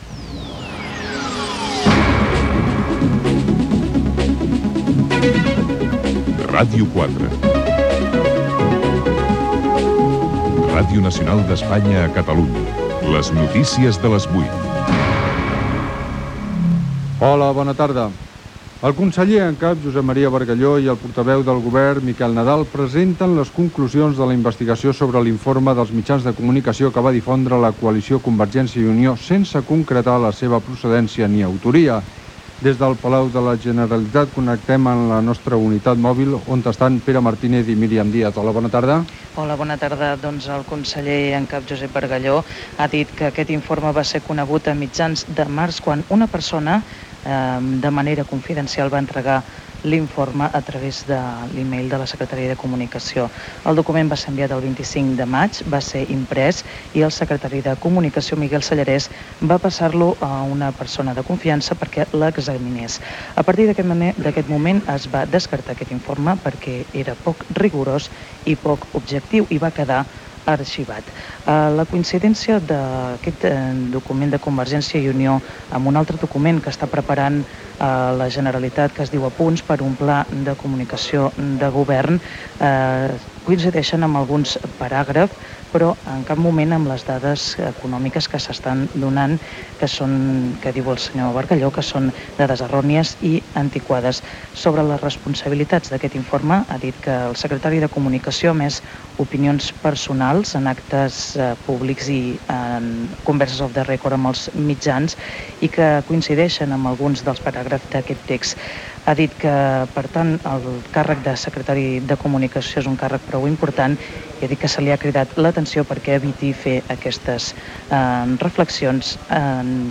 Informe sobre mitjans de comunicació fet per Convergència i Unió, les set vagueries catalanes, servei català de trànsit. Indicatiu de l'emissora, promo del programa "Serà la lluna", indicatiu de l'emissora.
Informatiu